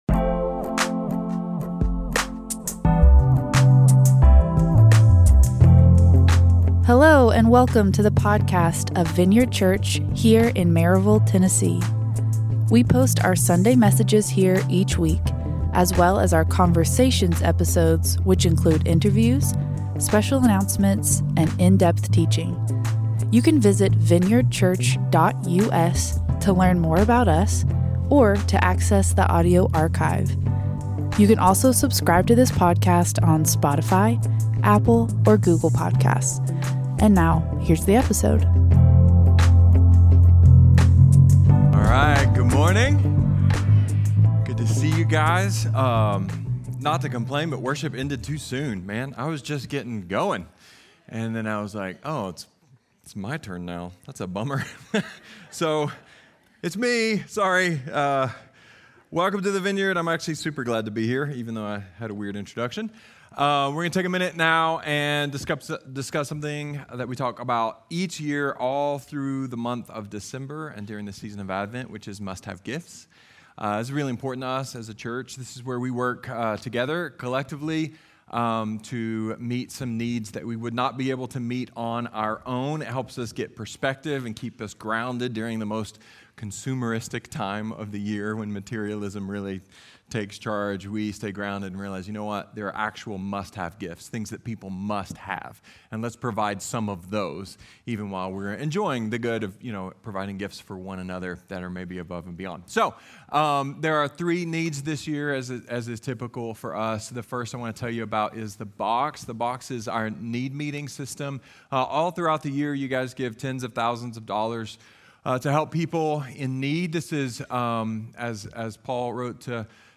A message from the series "Advent: Day of Peace."